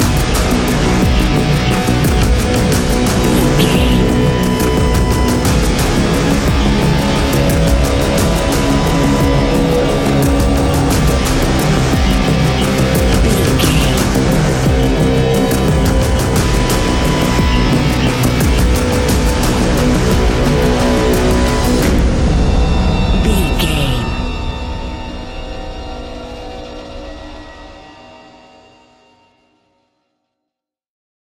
Ionian/Major
B♭
industrial
dark ambient
EBM
synths